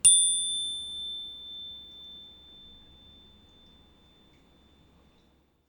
2011_벨.mp3